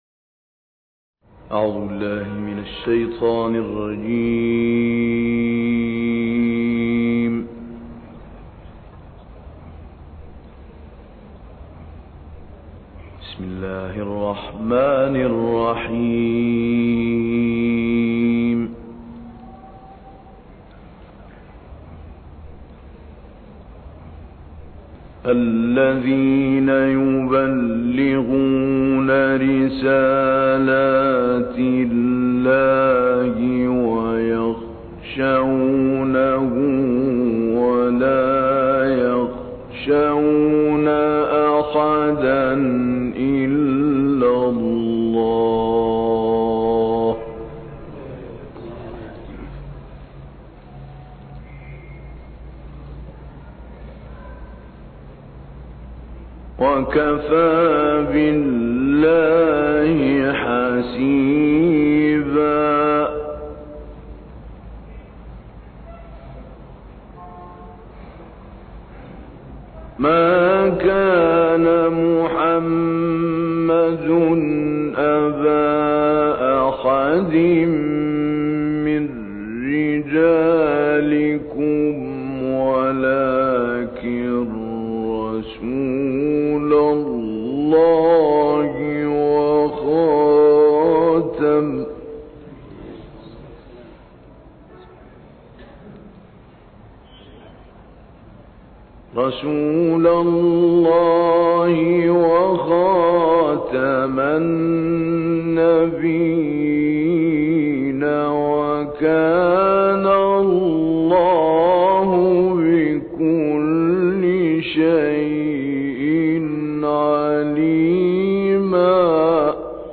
دانلود قرائت سوره های احزاب 39 تا 50 و نازعات 27 تا 33 - استاد محمود علی البنا